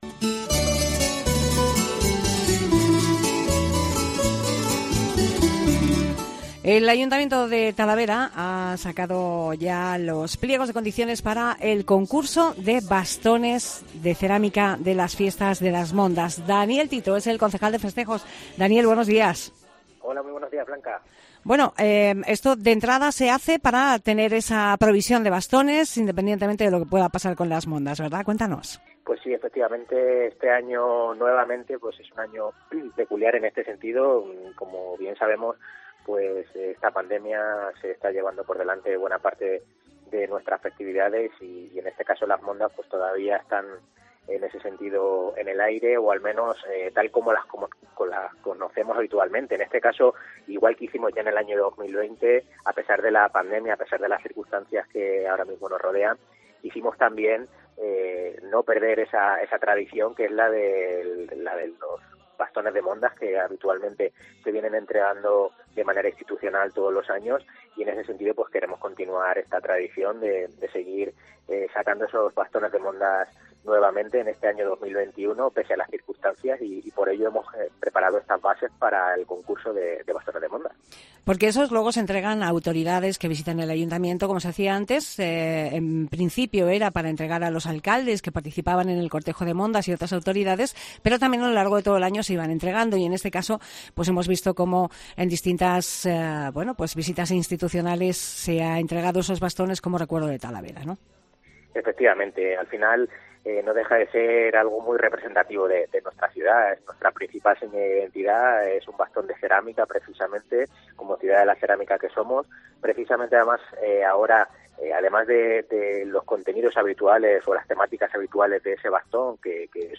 Declaraciones de Daniel Tito, concejal de Festejos